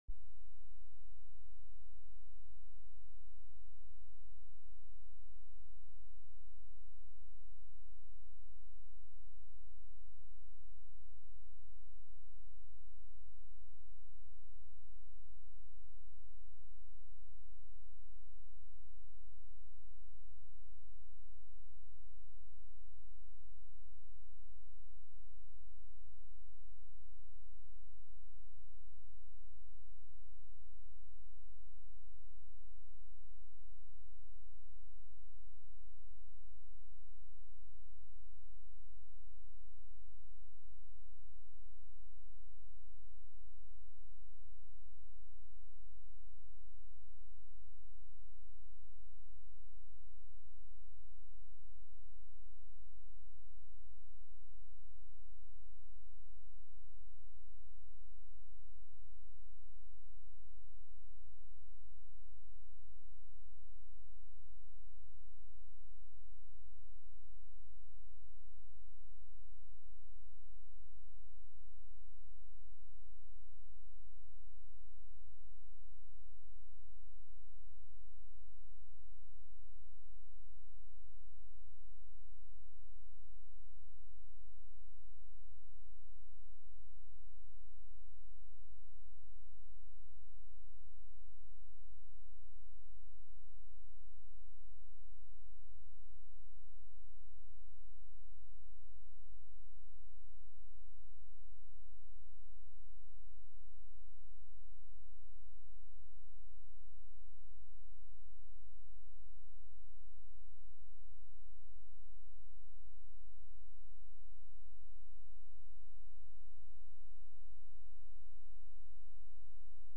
Christmas-Eve-Service-2022.mp3